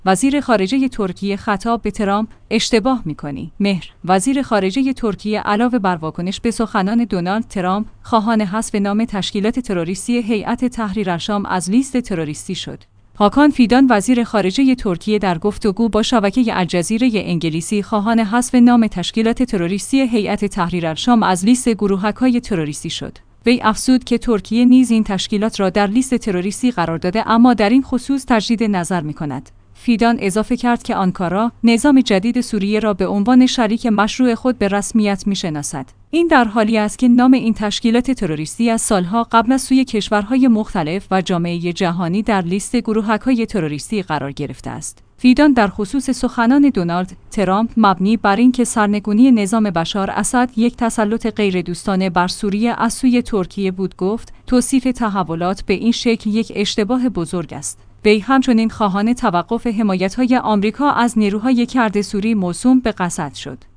هاکان فیدان وزیر خارجه ترکیه در گفتگو با شبکه الجزیره انگلیسی خواهان حذف نام تشکیلات تروریستی هیئت تحریرالشام از لیست گروهک‌های تروریستی شد.